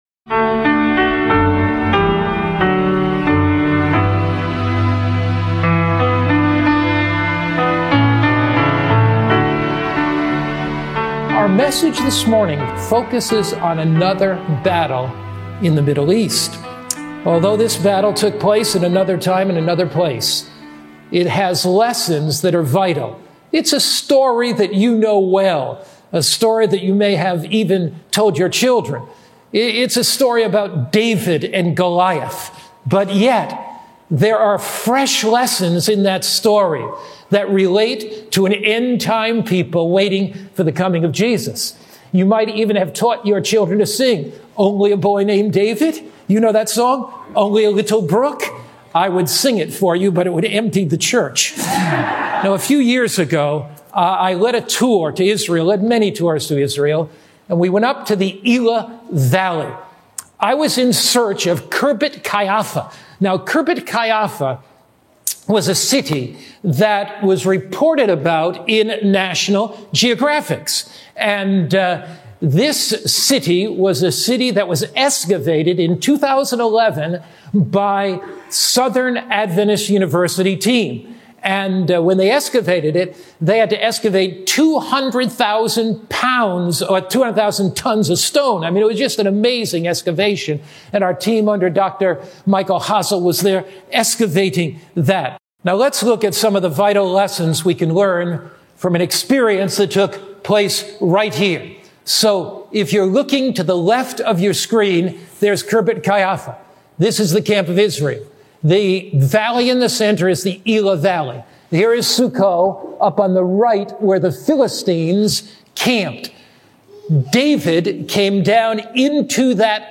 This powerful sermon unpacks the story of David and Goliath to show how faith, not fear, leads to victory over life’s biggest challenges. With spiritual tools like prayer, God’s Word, and trust in Christ—the ultimate Giant Slayer—you can face every battle with bold confidence and hope.